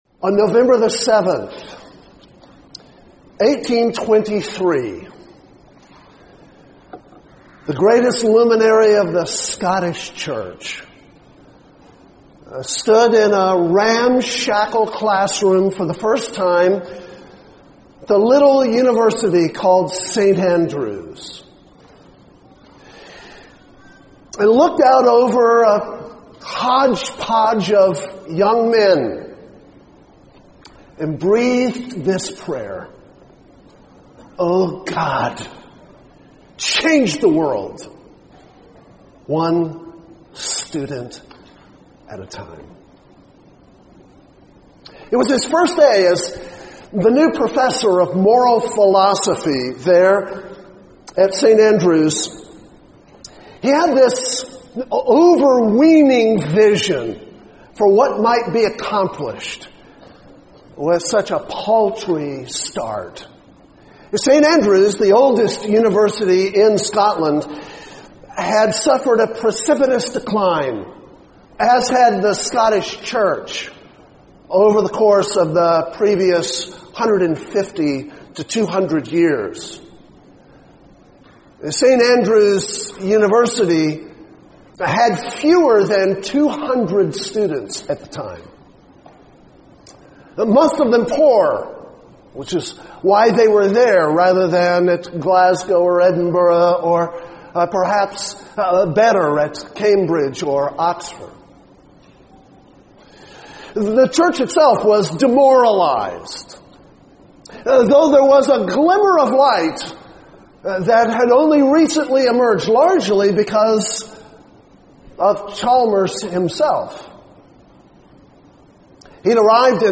2006 Plenary Talk | 0:50:40 | All Grade Levels, Culture & Faith
The Association of Classical & Christian Schools presents Repairing the Ruins, the ACCS annual conference, copyright ACCS.